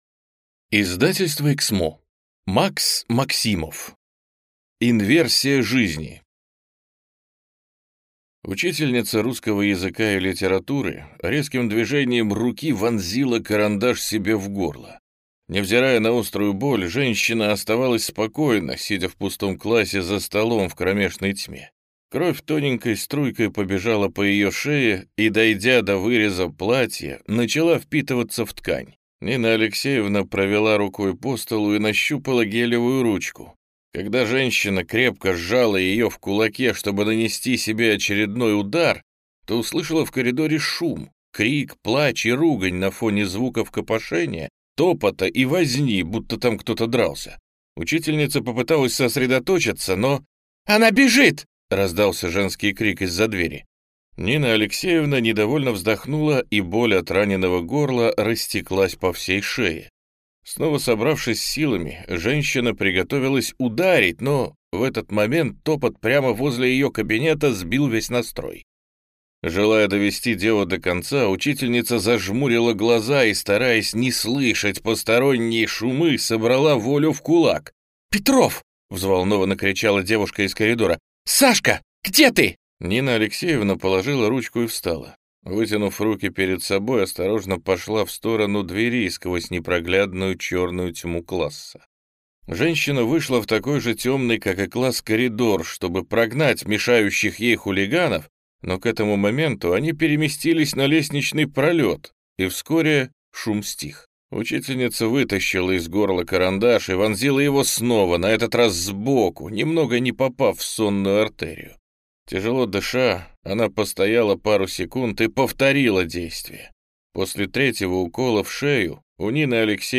Аудиокнига Инверсия жизни | Библиотека аудиокниг
Прослушать и бесплатно скачать фрагмент аудиокниги